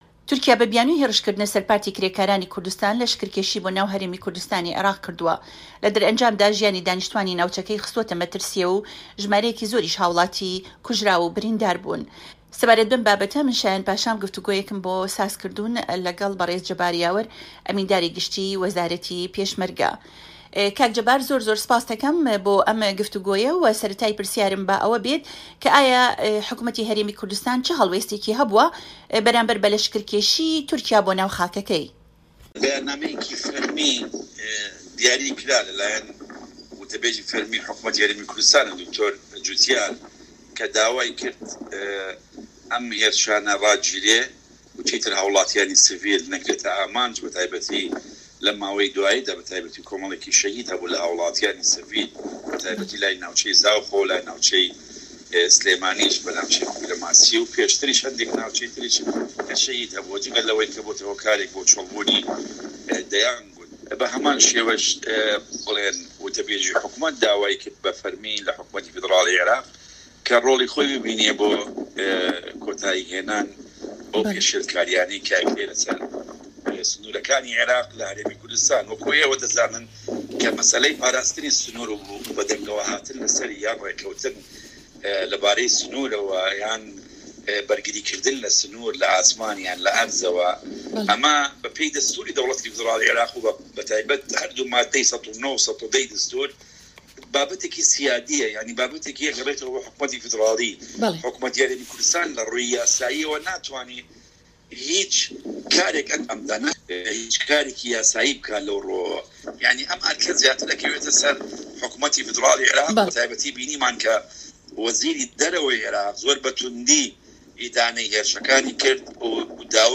گفتوگۆ لە گەڵ جەبار یاوەر